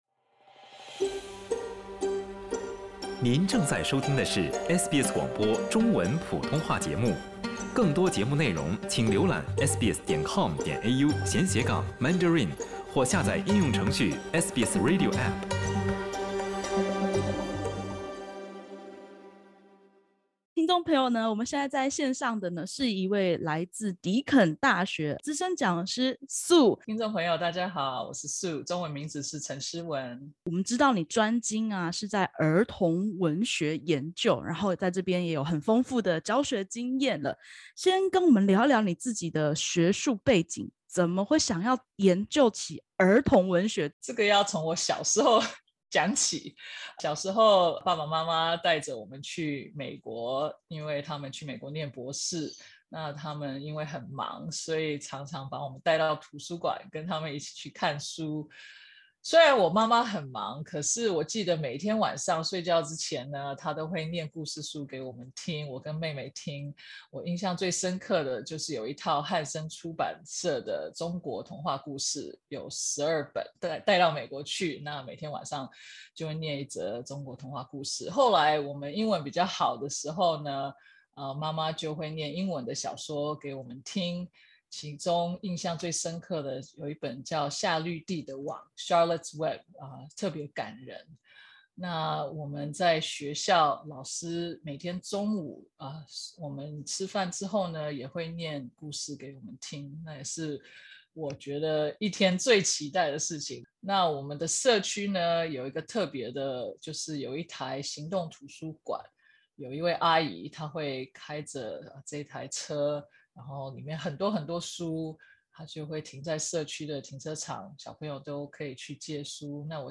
（点击首图收听采访音频） （本文系SBS中文原创内容，未经许可，不得转载。